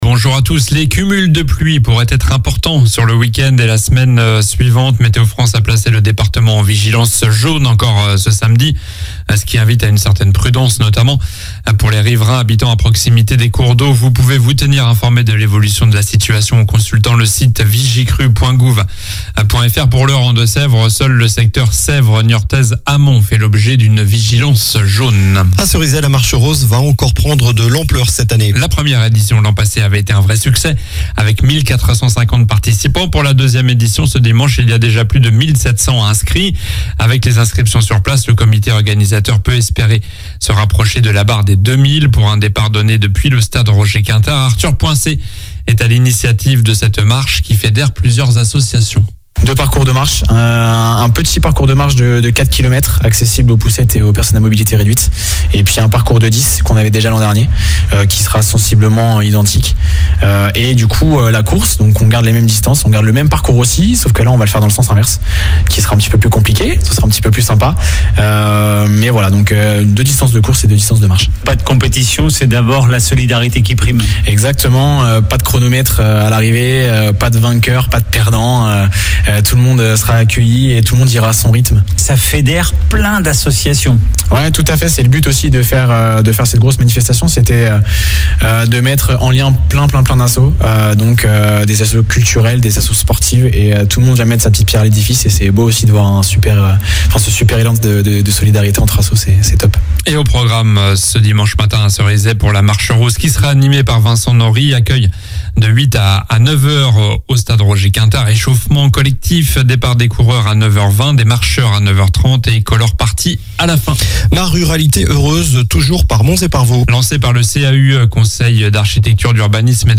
Journal du samedi 28 octobre (matin)